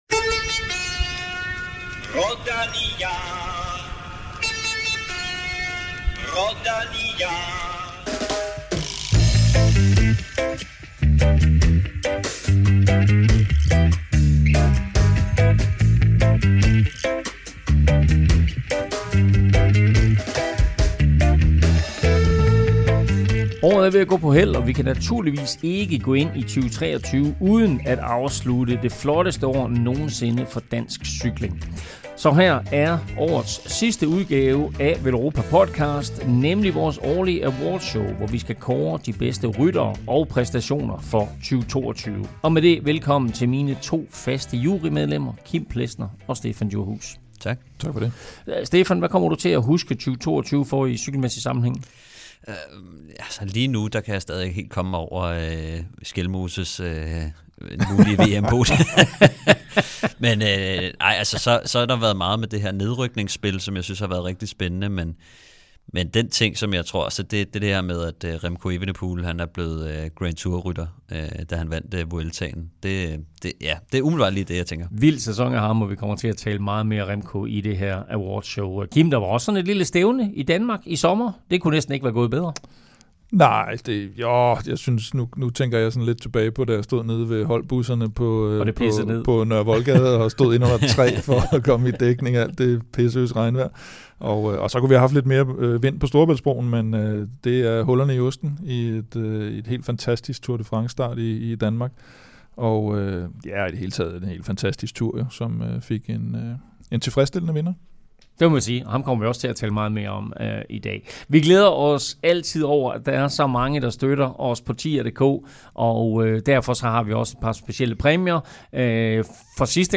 Du kan også høre interviews med et par af de nominerede danskere, nemlig Michael Mørkøv og Mattias Skjelmose, der blandt andet giver sig selv karakterer for sæsonen der er gået. Og så er der naturligvis årets absolut sidste quiz.